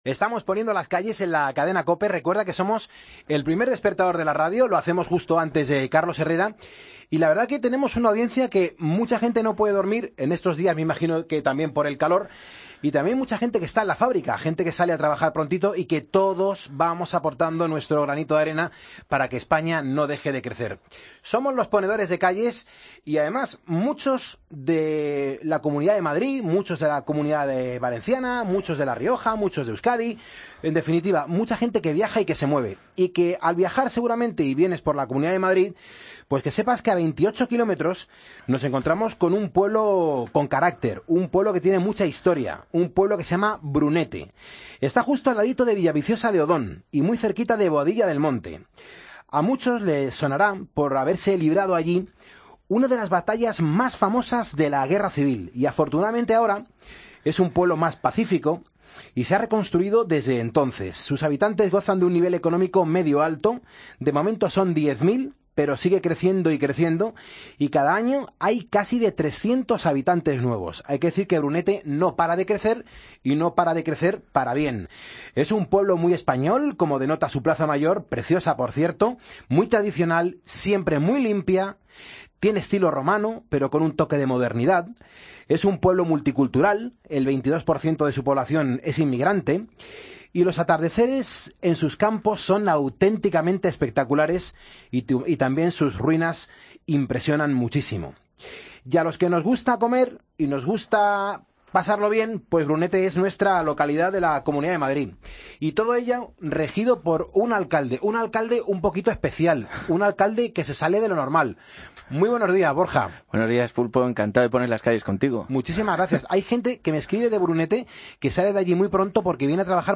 AUDIO: Charlamos con su alcalde, Borja Gutiérrez.